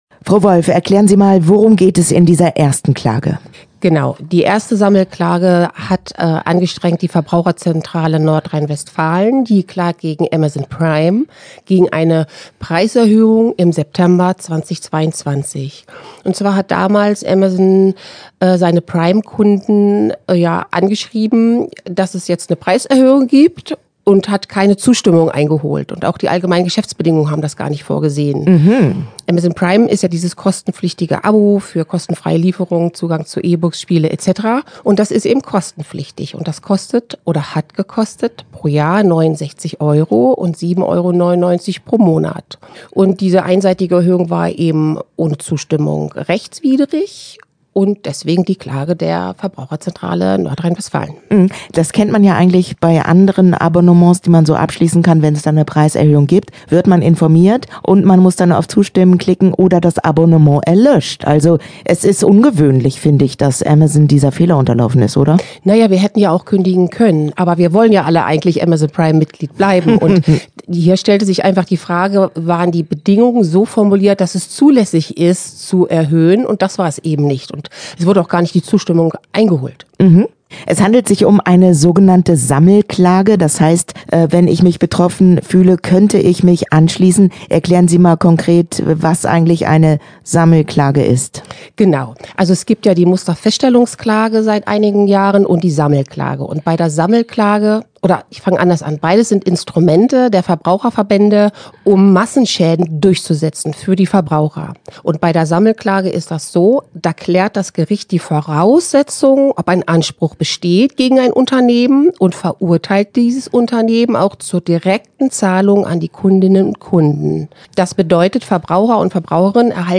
Verbraucherschutz im Gespräch - Thema heute: Zwei Sammelklagen gegen Amazon - Okerwelle 104.6